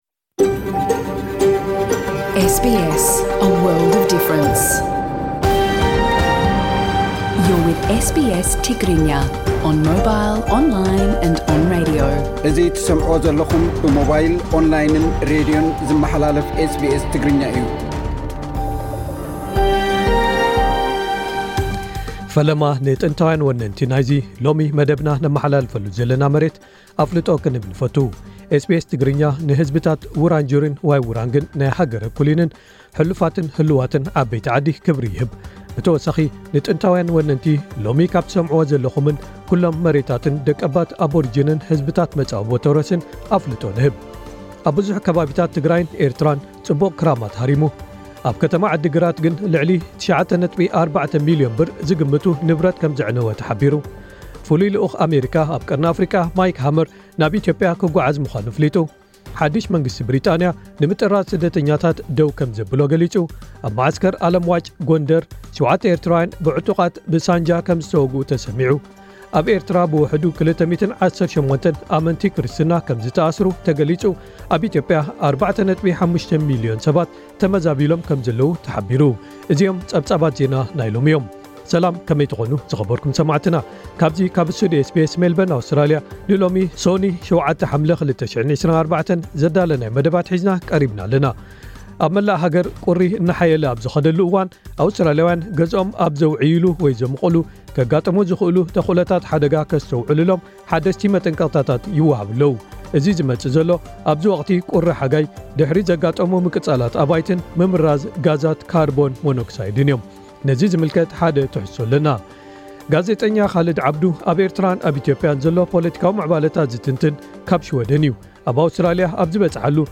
ጸብጻባት ዜና ናይ ሎሚ:
ኣብ ኣውስትራሊያ ኣብ ዝበጽሓሉ ብዛዕባ ታሪኹን ዘካይዶም ንጥፈታትን ሰፊሕ ዕላል ኣካይድናሉ ኣለና።